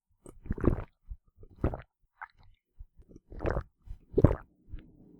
drink.opus